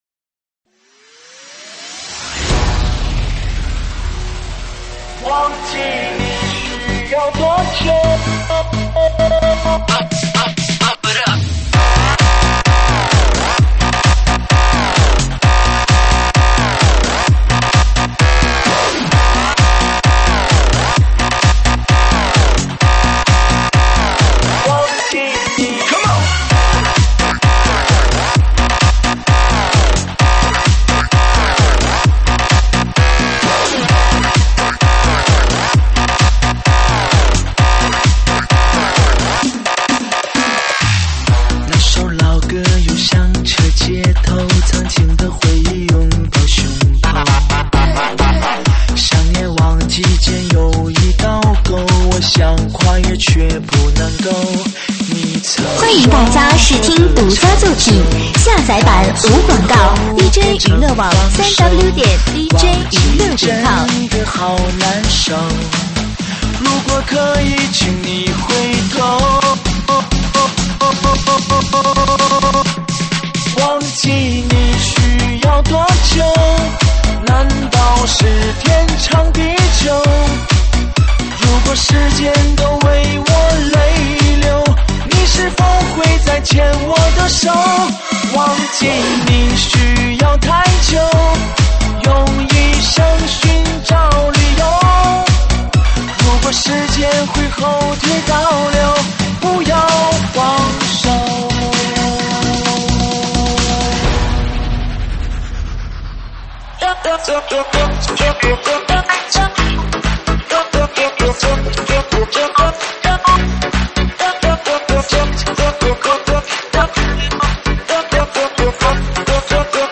中文慢摇
舞曲类别：中文慢摇